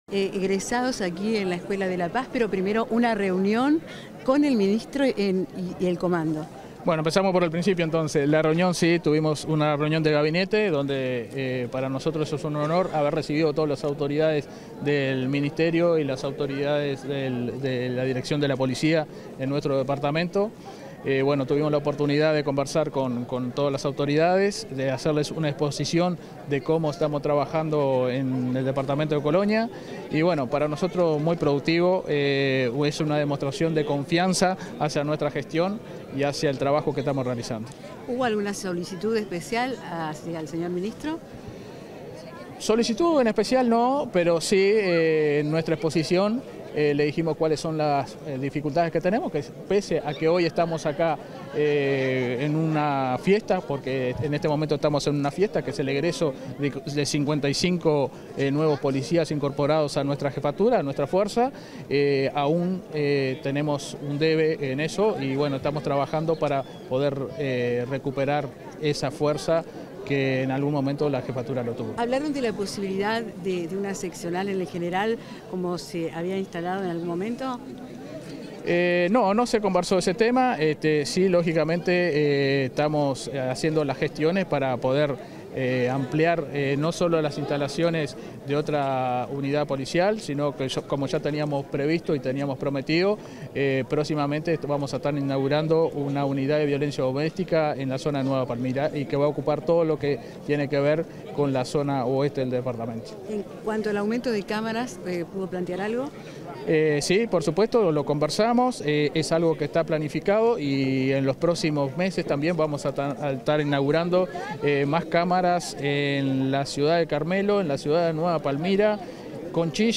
Declaraciones del jefe de Policía de Colonia, Fabio Quevedo
Declaraciones del jefe de Policía de Colonia, Fabio Quevedo 01/07/2024 Compartir Facebook X Copiar enlace WhatsApp LinkedIn Tras la ceremonia de egreso de la XCVII Promoción de Agentes de la Escuela Policial de Escala Básica, este 1 de junio, el jefe de Policía de Colonia, Fabio Quevedo realizó declaraciones a la prensa.